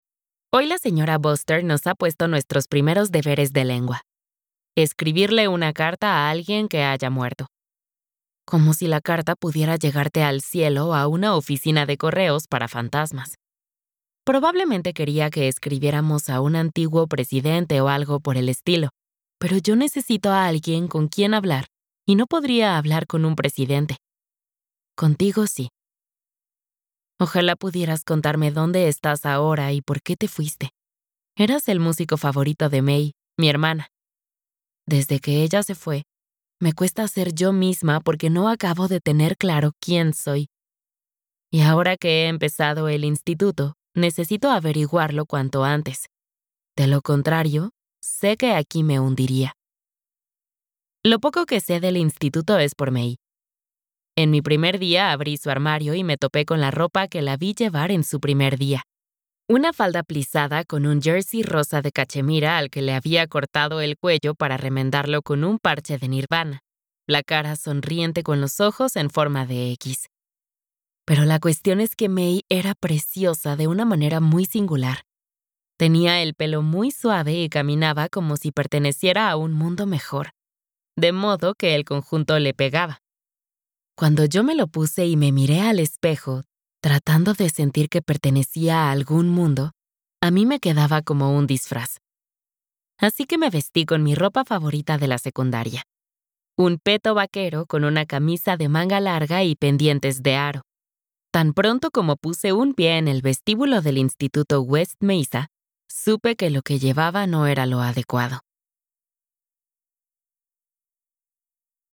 Audiolibro Cartas de amor a los muertos (Love Letters to the Dead)